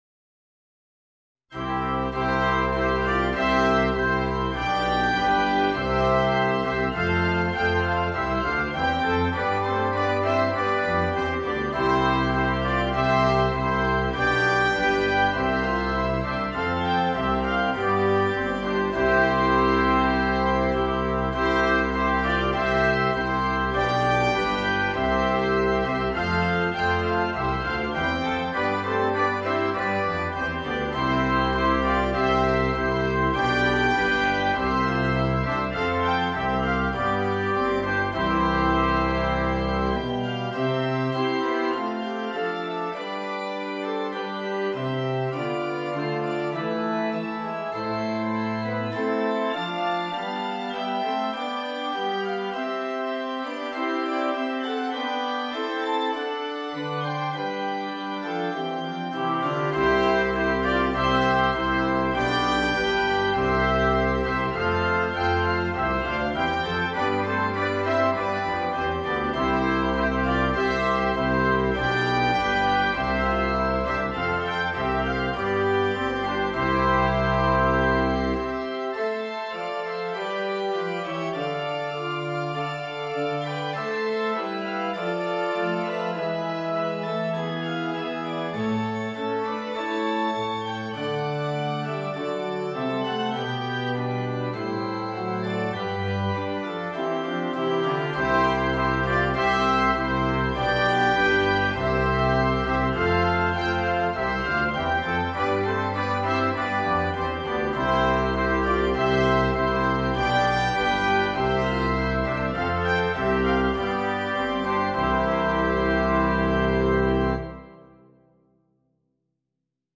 avec organe
Classique
Partie 1: Trompette sib, Cornet à Pistons sib
Partie 3: Cor d’harmonie
Partie 4: Trombone, Euphonium – Clé de fa
Accompagnement d'orgue